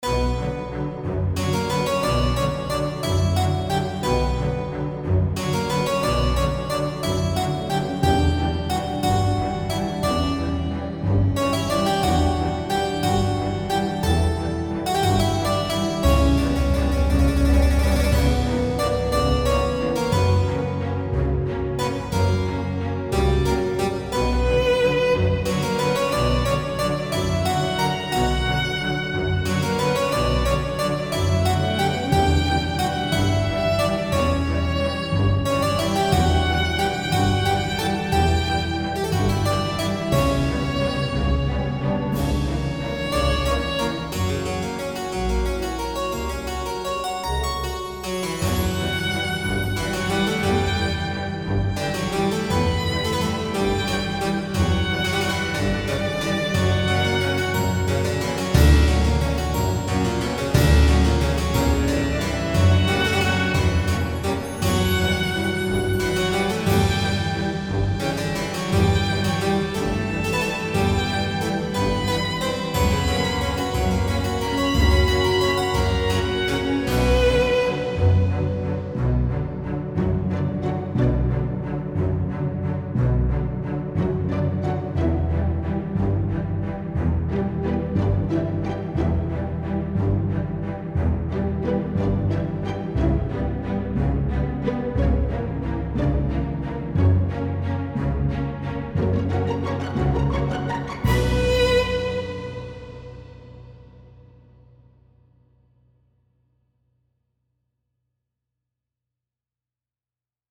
" is the waltz heard in the castle section of the game.
Sounds a lot more majestic and "wider" than the original.